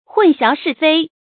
注音：ㄏㄨㄣˋ ㄒㄧㄠˊ ㄕㄧˋ ㄈㄟ
混淆是非的讀法